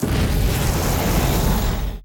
some SFX
Firespray 1.ogg